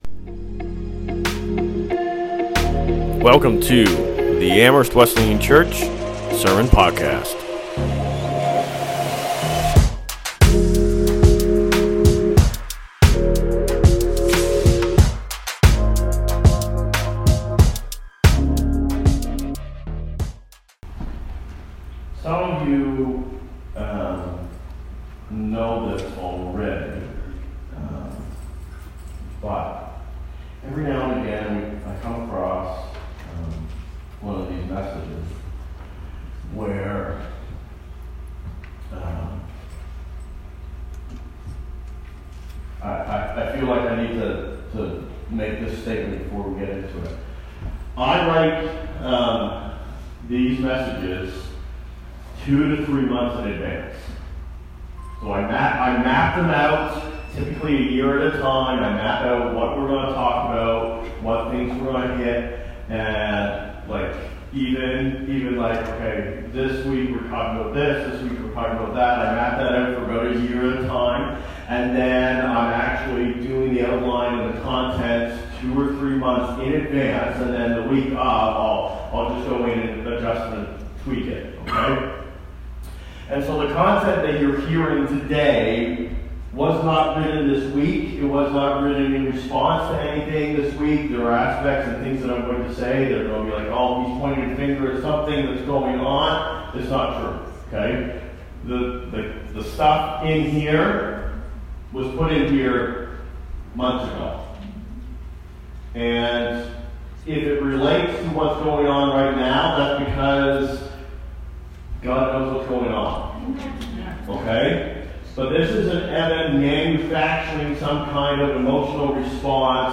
2025 Current Sermon Loving Sinners God loves sinners.